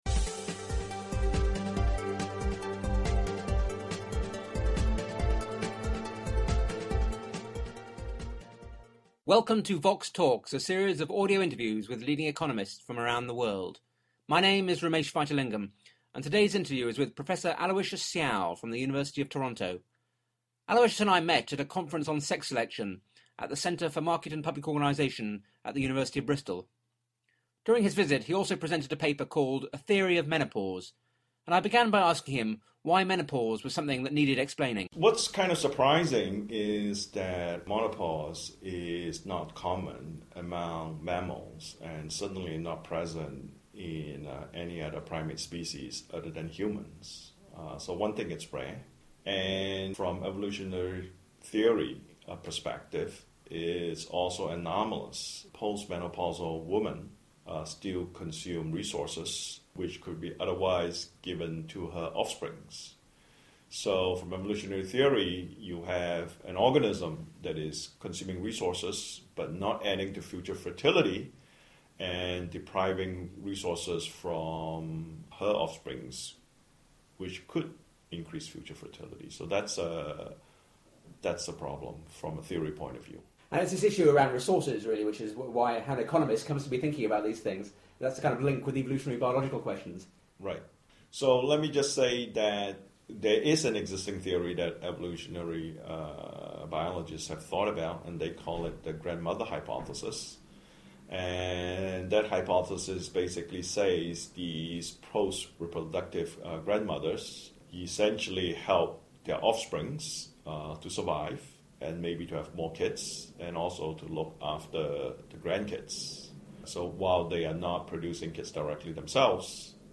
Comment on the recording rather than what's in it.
The interview was recorded at the Centre for Market and Public Organisation in Bristol in October 2010.